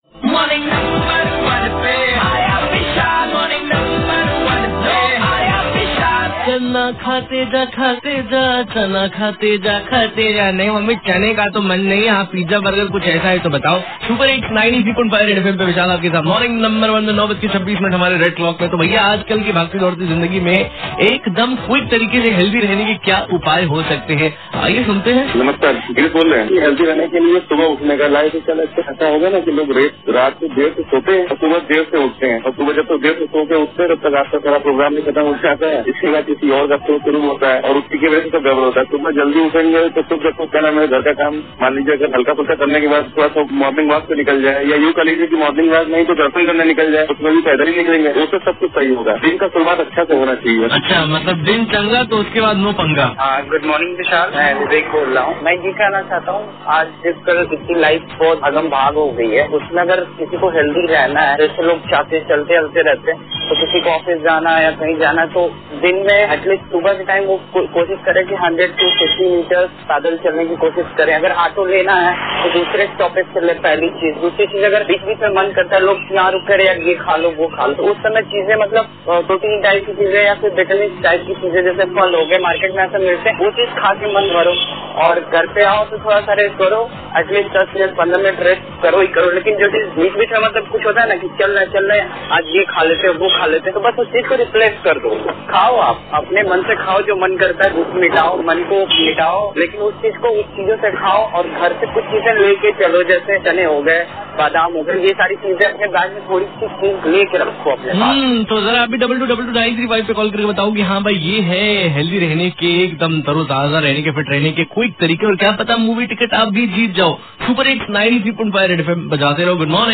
CALLER